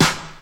• 80s Studio Hip-Hop Snare Drum Sound E Key 03.wav
Royality free snare drum sound tuned to the E note. Loudest frequency: 2480Hz